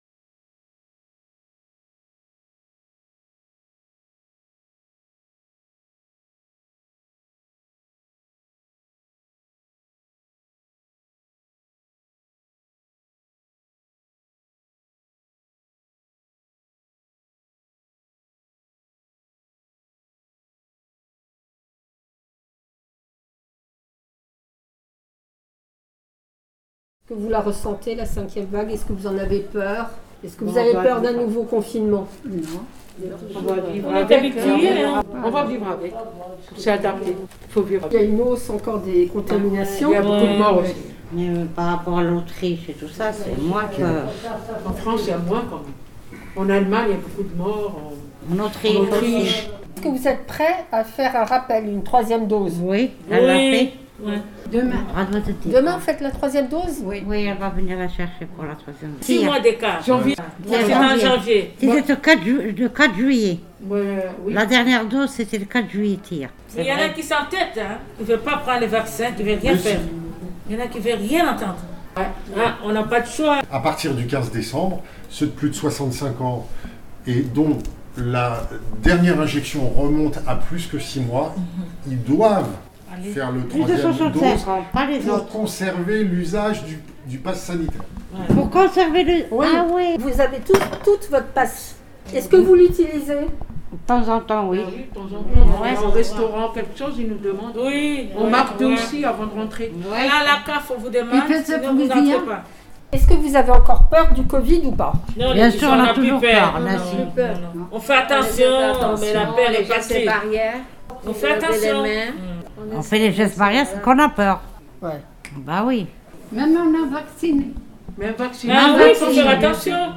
Cette fois, il a été question au Café Social de la cinquième vague. L’occasion, aussi, d’aborder les tensions en Guadeloupe en raison de l’obligation vaccinale imposée aux soignants par le gouvernement. Beaucoup de femmes connaissent les plages et les fruits exotiques des îles antillaises, elles se sont également interrogées sur le statut de ces îles, un statut qui leur rappelle celui de l’Algérie avant l’indépendance.